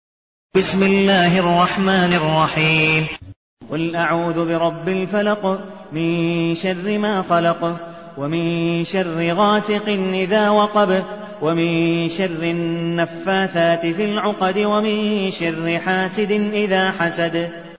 المكان: المسجد النبوي الشيخ: عبدالودود بن مقبول حنيف عبدالودود بن مقبول حنيف الفلق